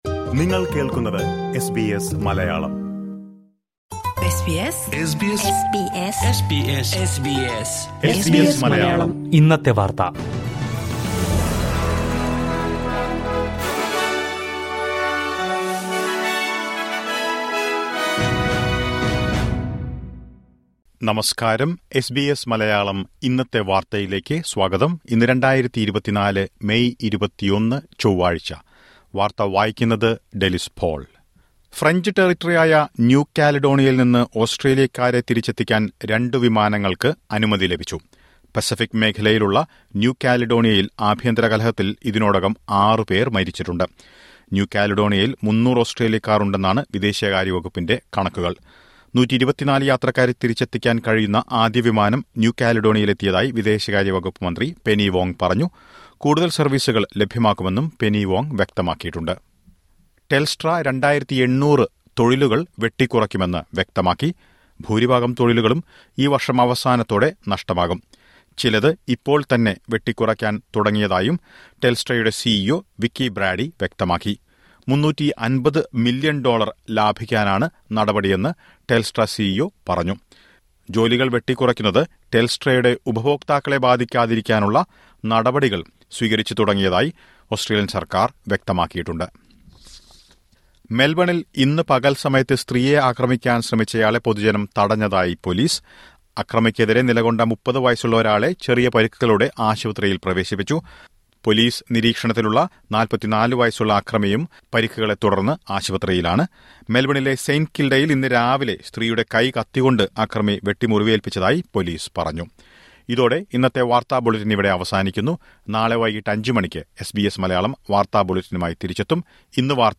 2024 മെയ് 21ലെ ഓസ്‌ട്രേലിയയിലെ ഏറ്റവും പ്രധാന വാര്‍ത്തകള്‍ കേള്‍ക്കാം...